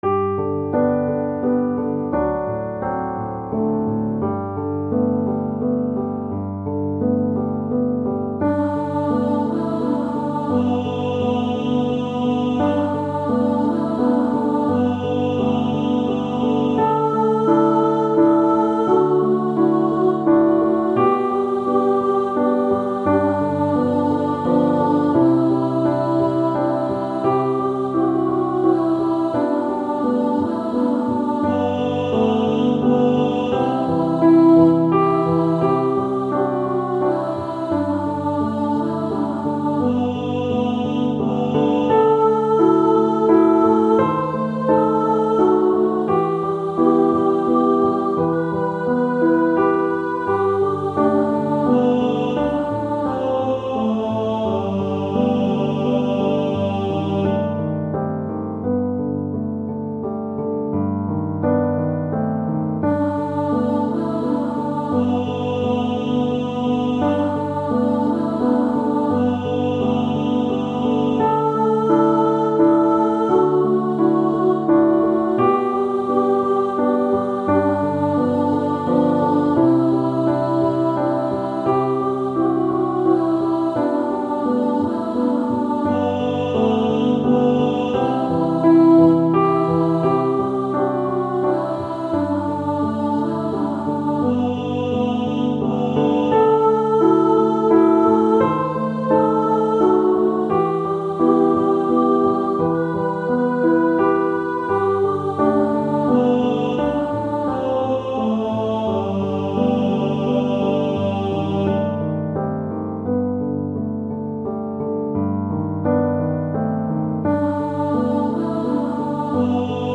Podkład muzyczny